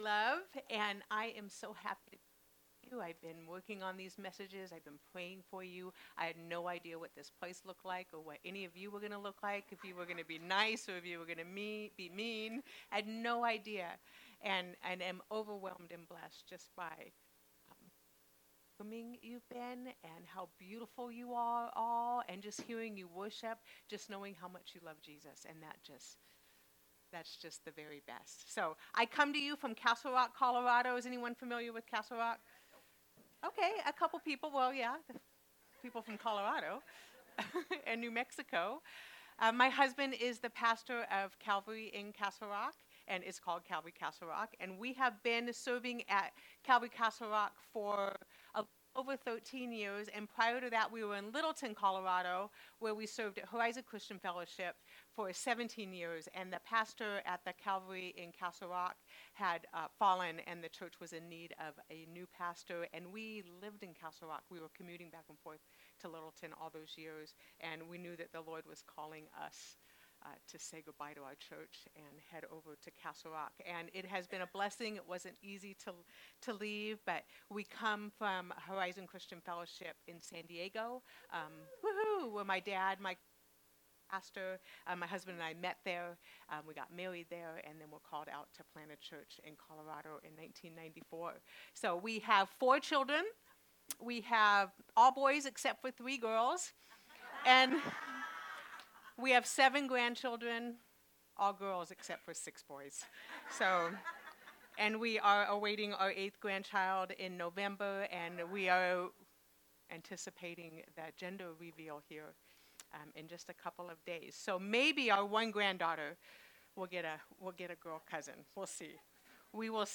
Sermons | Calvary Chapel Pahrump Valley